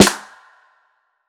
HFMSnare1.wav